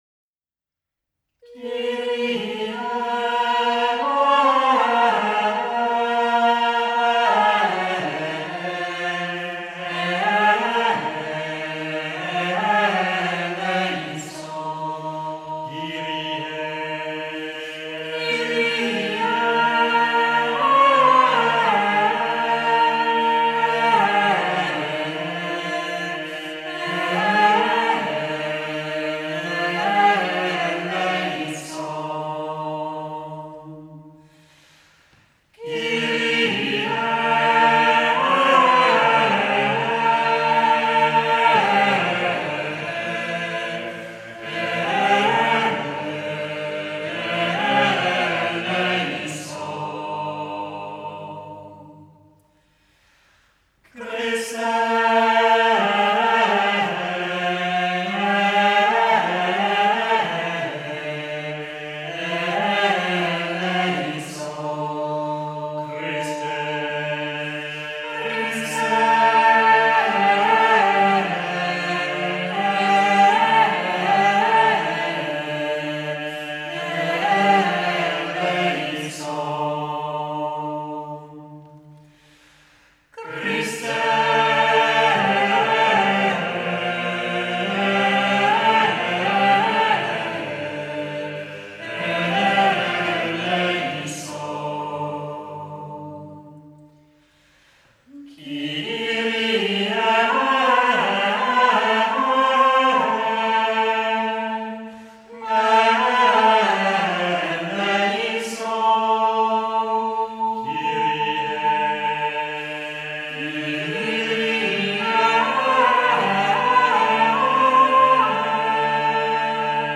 Two forms of sacred music are recognised as being perfectly and particularly related to the Roman liturgy: Gregorian Chant and Sacred Polyphony, though Gregorian Chant is to be given pride of place.
The Mixed Choir, the Choir of St Radegund; the Ladies Choir, the Choir of Our Lady of Ransom; and the Youth Choir.